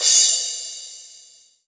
• Crash Cymbal Single Shot G# Key 06.wav
Royality free drum crash tuned to the G# note.
crash-cymbal-single-shot-g-sharp-key-06-QlV.wav